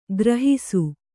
♪ grahisu